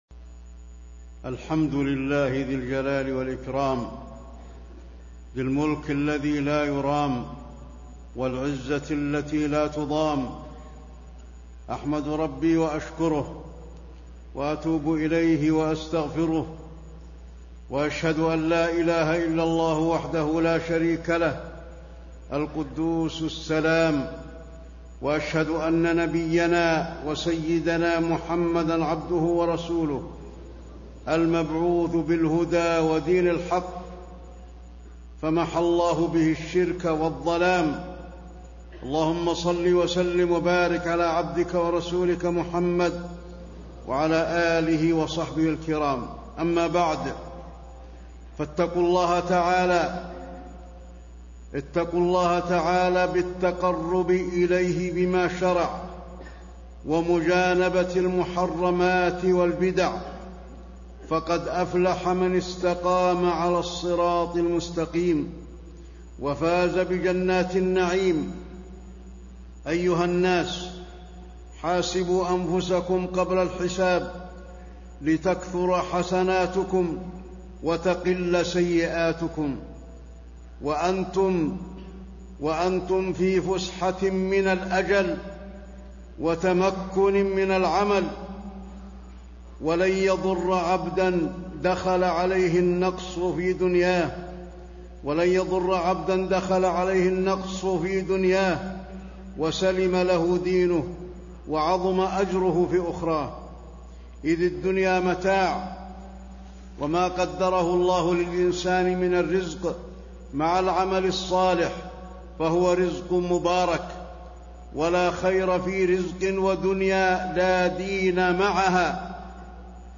تاريخ النشر ١٢ ربيع الثاني ١٤٣٤ هـ المكان: المسجد النبوي الشيخ: فضيلة الشيخ د. علي بن عبدالرحمن الحذيفي فضيلة الشيخ د. علي بن عبدالرحمن الحذيفي الإكثار من عمل الخير